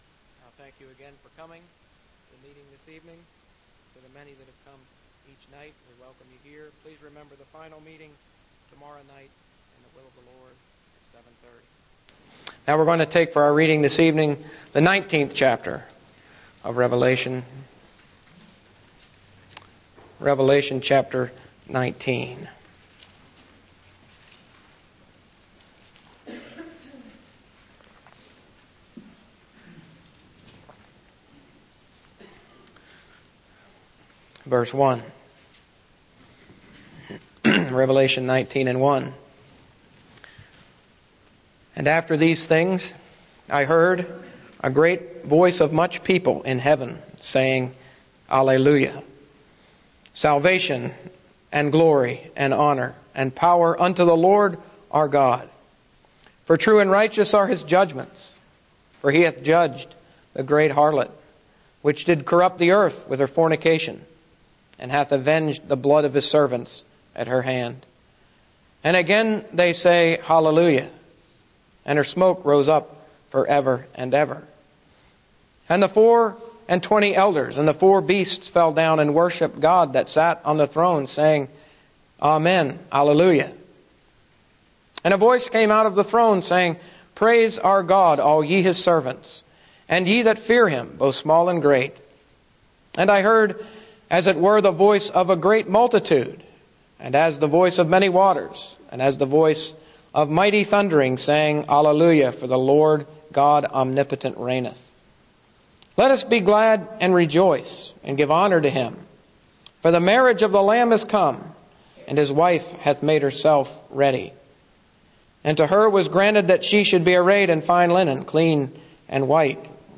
Unveiling Revelation Service Type: Gospel Preaching Topics: Prophecy